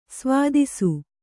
♪ svādisu